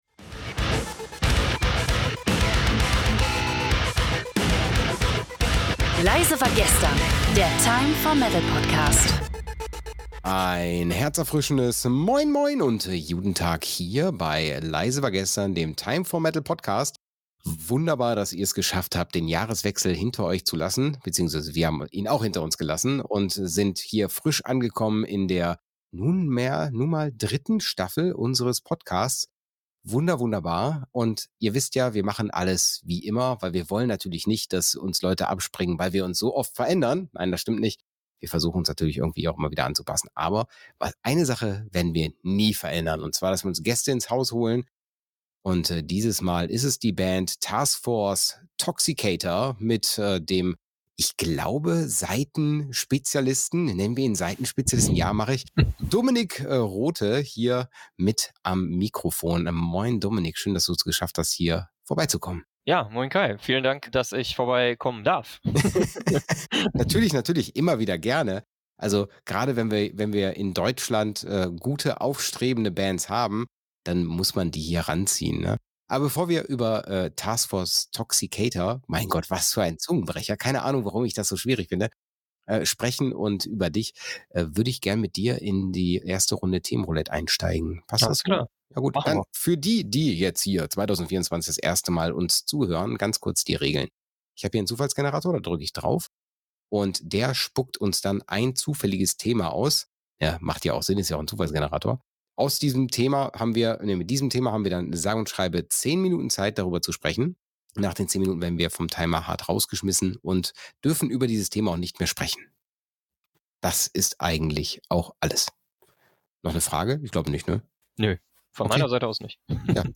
(0:40:30) - Outro & Outrosong